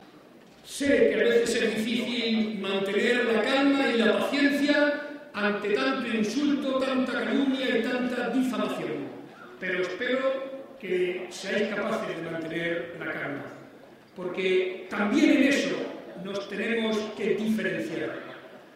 El secretario regional del PSOE y presidente de C-LM, participó en la tradicional comida de Navidad de los socialistas de Albacete.
Cortes de audio de la rueda de prensa